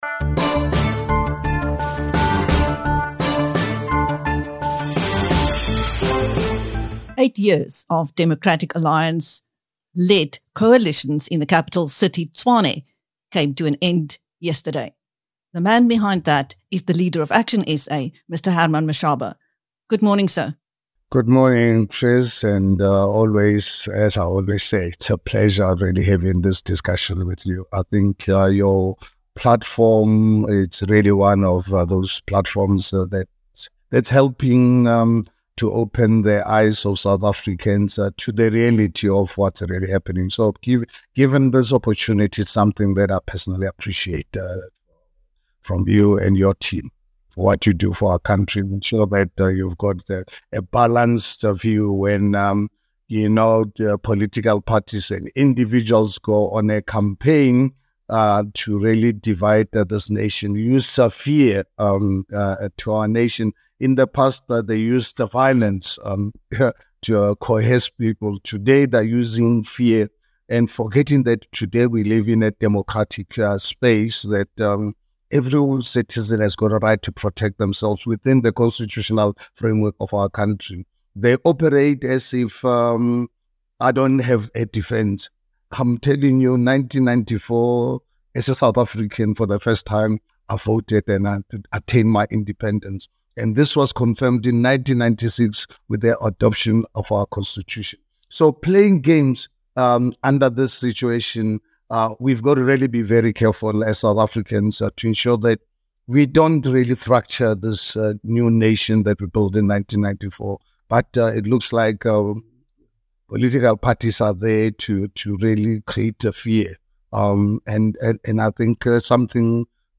Speaking to BizNews following the election of an ActionSA Mayor in Tshwane, he expresses determination to turn the capital city around.